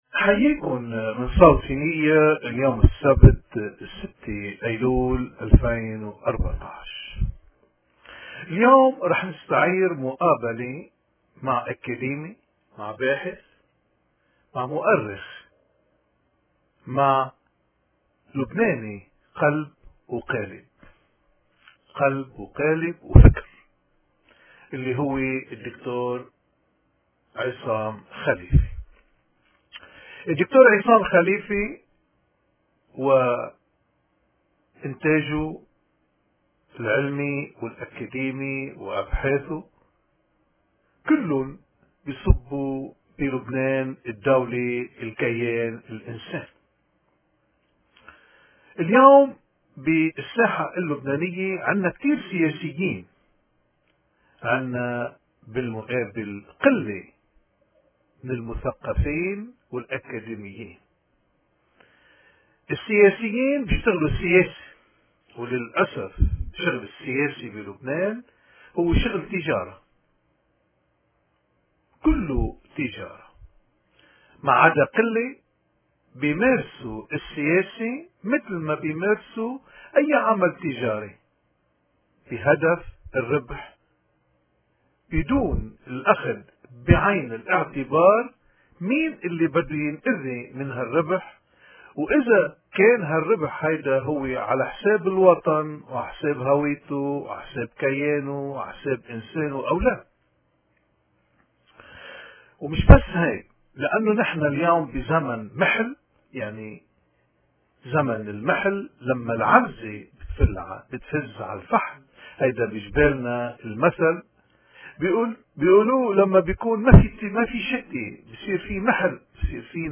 في أعلى التعليق والمقابلة بالصوت فورماتMP3